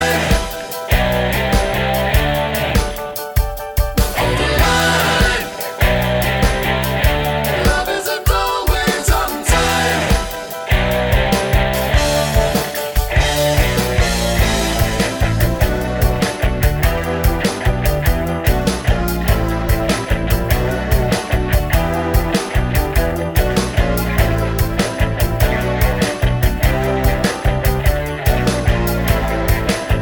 Three Semitones Down Soft Rock 3:50 Buy £1.50